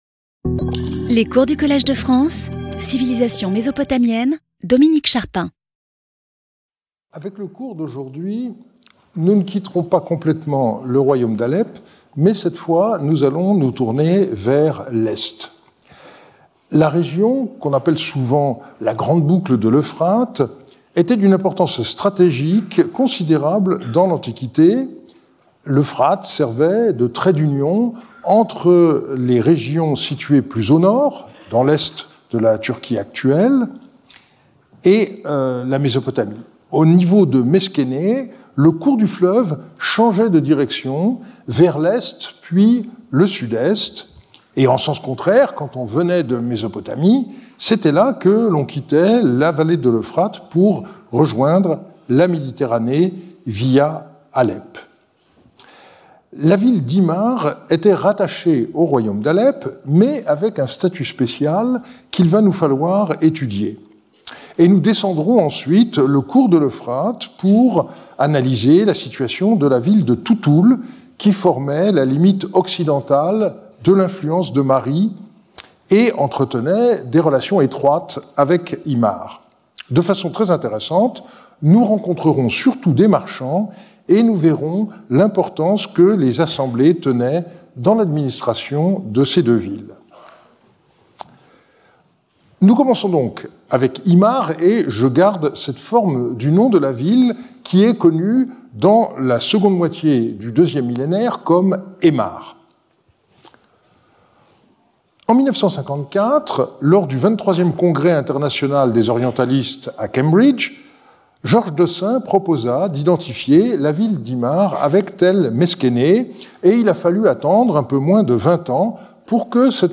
Lecture audio
Sauter le player vidéo Youtube Écouter l'audio Télécharger l'audio Lecture audio Intervenant(s) Dominique Charpin Professeur du Collège de France Événements Précédent Cours 08 Déc 2025 11:00 à 12:00 Dominique Charpin Introduction.